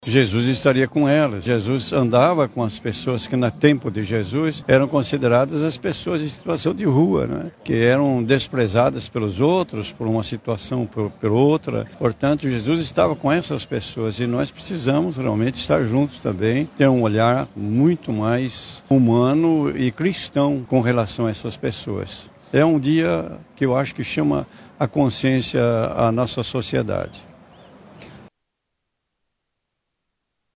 Em referência ao Dia de Luta da População em Situação de Rua, a Rede Pop Rua, realizou nesta terça-feira, 19 de agosto, uma mobilização na Praça da Matriz, no Centro de Manaus.
O Arcebispo Emérito de Manaus, Dom Luiz Soares Vieira, também participou da ação. Ele ressaltou o papel do Evangelho no compromisso com os mais pobres.